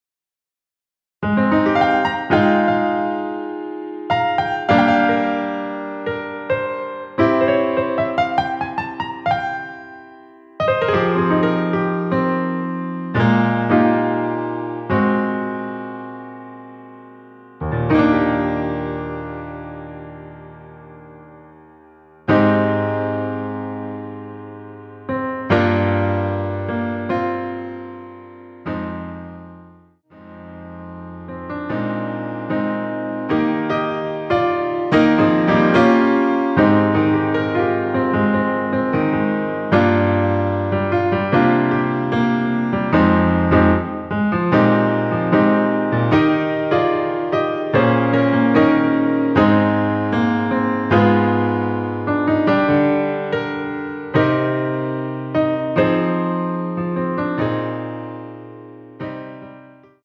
원키에서(+3)올린 MR입니다.
Eb
앞부분30초, 뒷부분30초씩 편집해서 올려 드리고 있습니다.
중간에 음이 끈어지고 다시 나오는 이유는